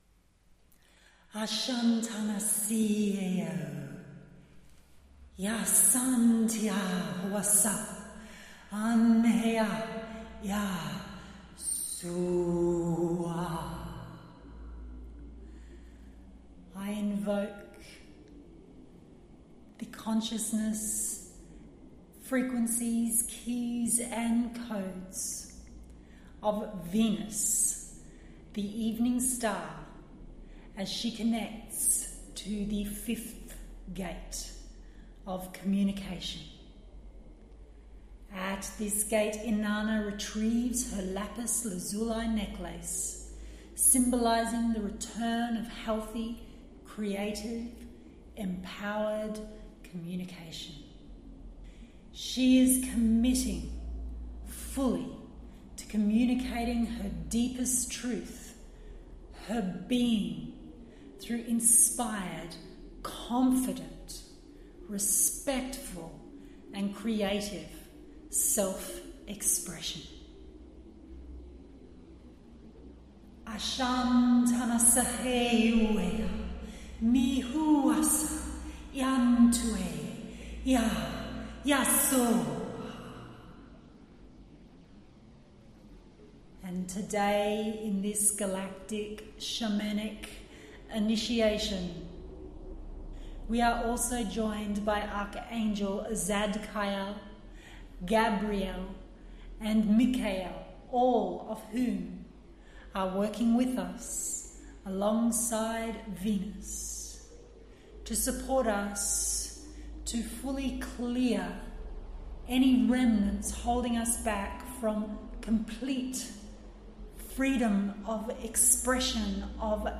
SNEAK PEEK of this AMAZING MEDITATION/TRANSMISSION – sharing the first 2 minutes of the 12 minute Celestial Resonance Meditation Journey, you will need to purchase the mp3 audio file to experience the full journey.